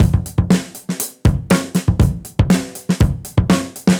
Index of /musicradar/dusty-funk-samples/Beats/120bpm
DF_BeatC_120-03.wav